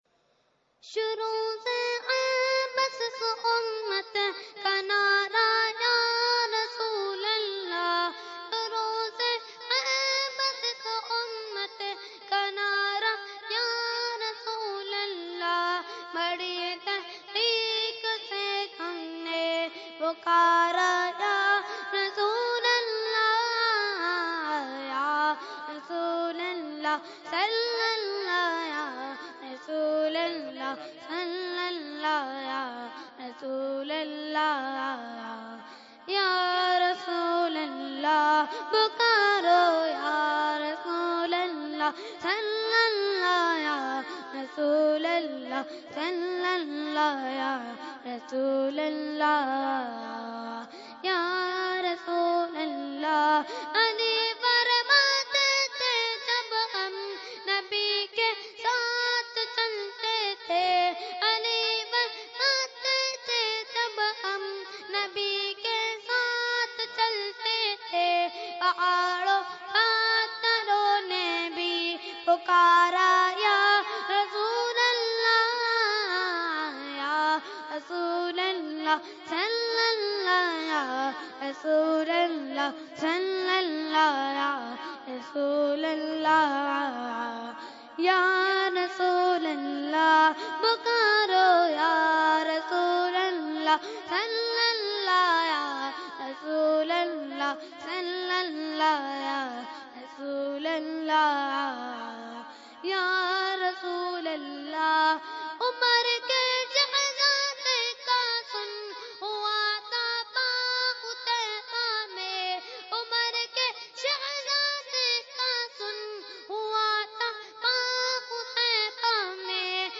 Category : Naat | Language : UrduEvent : Urs Makhdoome Samnani 2016